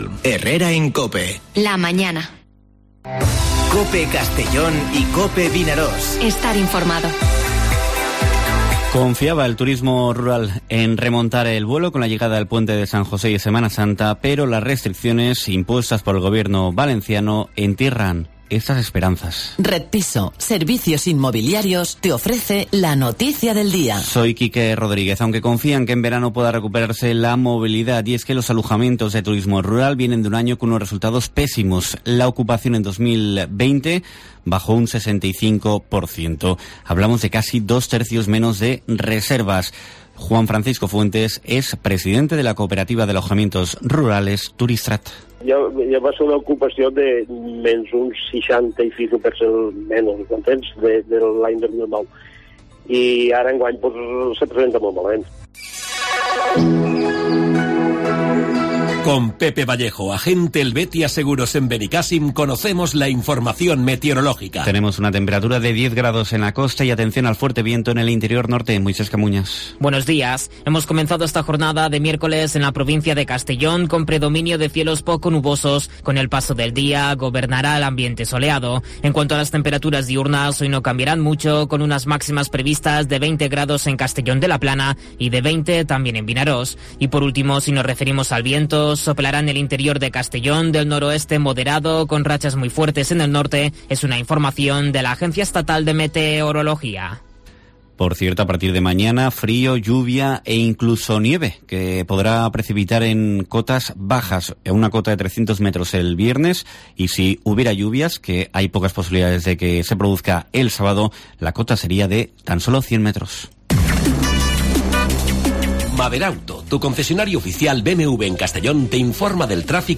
Informativo Herrera en COPE en la provincia de Castellón (17/03/2021)